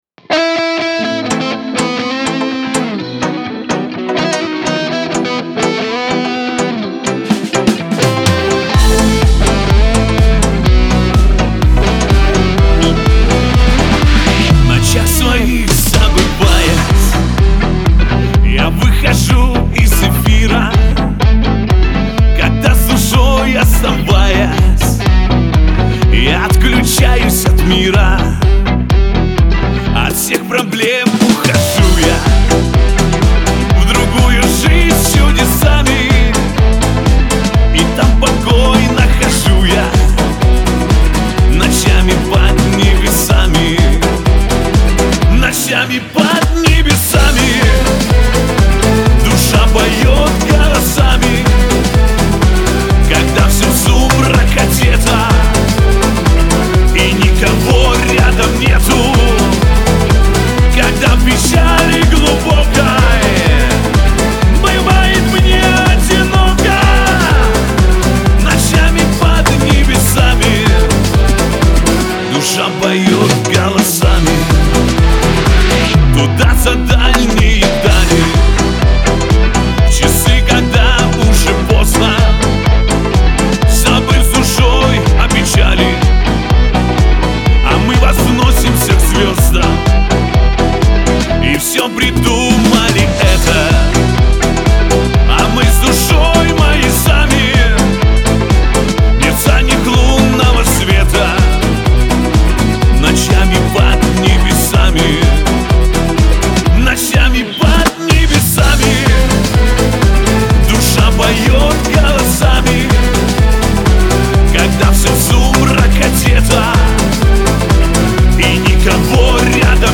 эстрада
диско